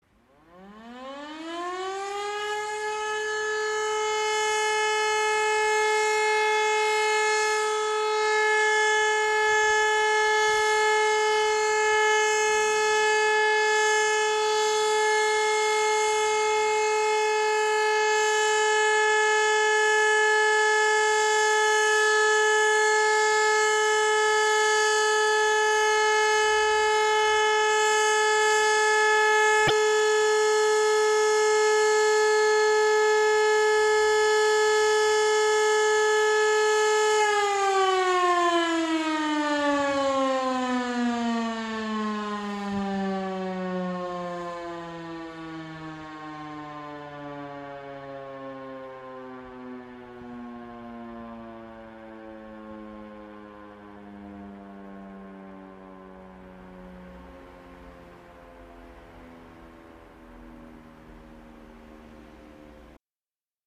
Sirenensignal Warnung
sirene.mp3